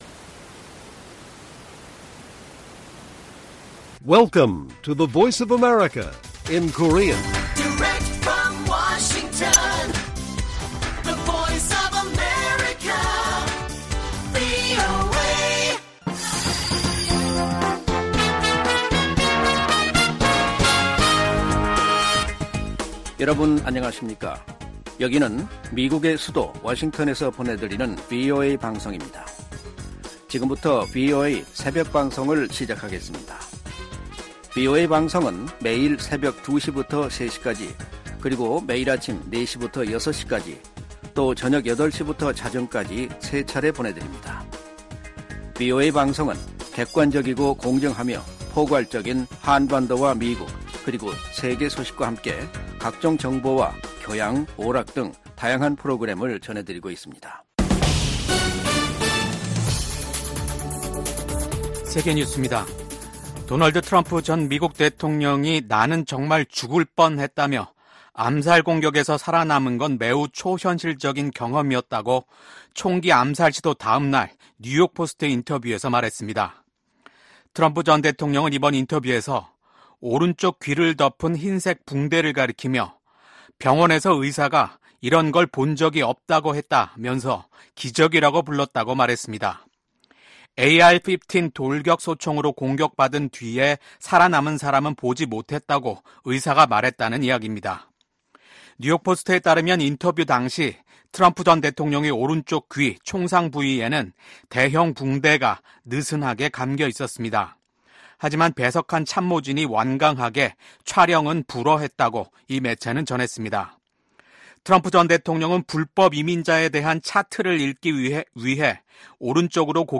VOA 한국어 '출발 뉴스 쇼', 2024년 7월 16일 방송입니다. 조 바이든 미국 대통령은 트럼프 전 대통령 피격 사건이 나자 대국민 연설을 통해 폭력은 결코 해답이 될 수 없다고 강조했습니다. 도널드 트럼프 전 대통령에 대한 총격 사건과 관련해 미국 정치권과 각국 정상은 잇달아 성명을 내고 트럼프 전 대통령의 빠른 쾌유를 기원했습니다.